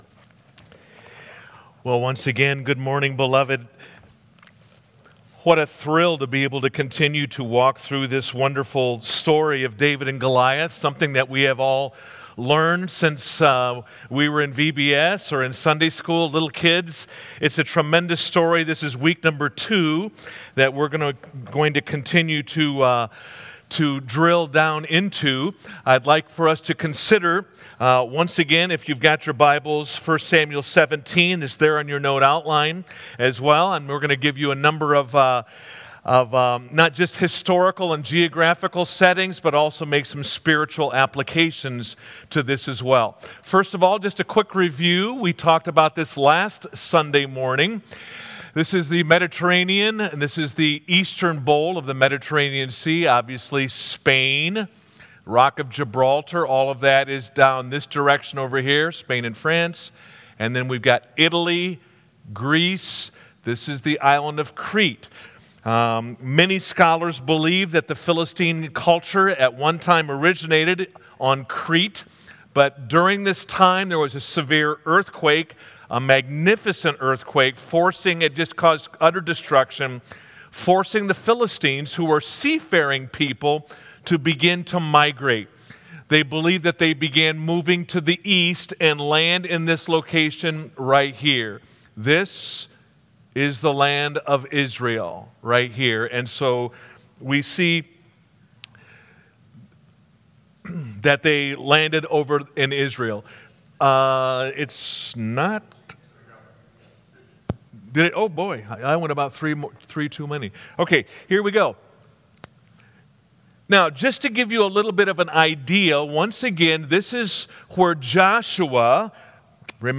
Sermons | California Road Missionary Church